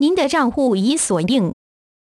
AXEFIAudio_zh_CN_AccountLocked.wav